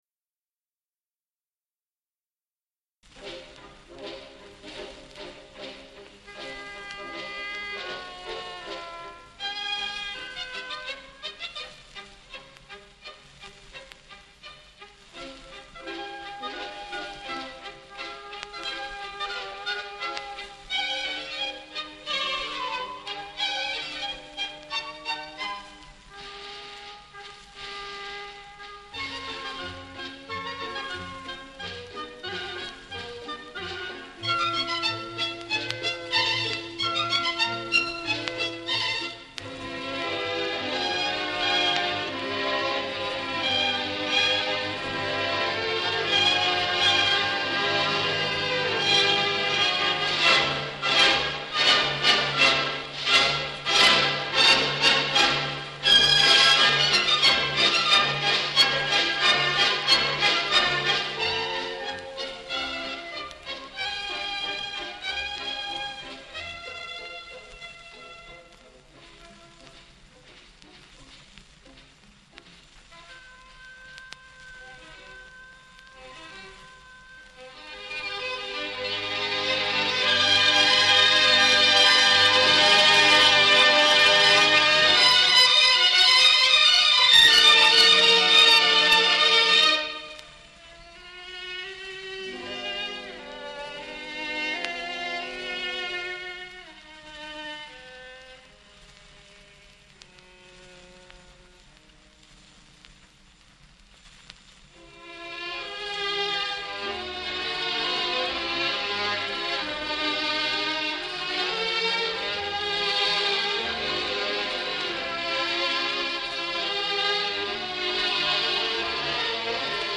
STRAUSS WALTZES - LUSH STRINGS